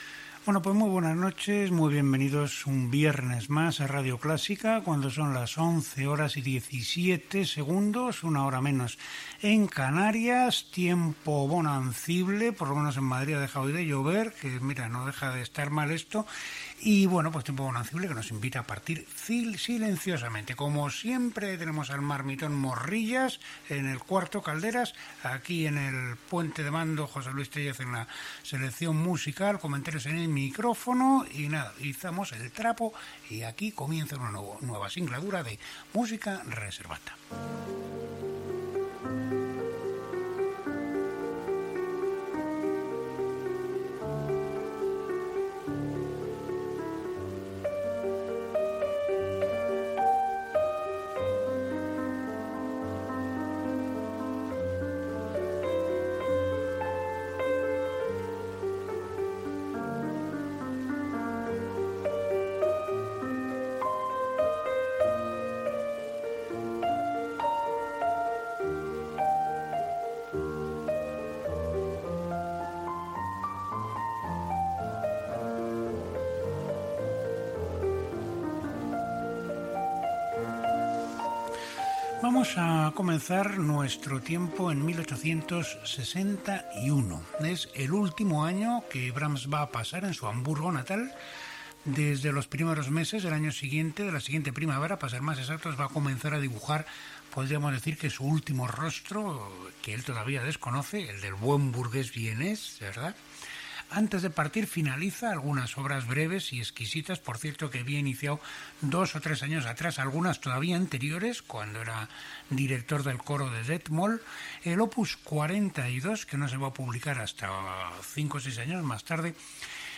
Hora, estat del temps, equip, fragment musical i explicació sobre la vida i obra de Brahms l'any 1861.
Musical
FM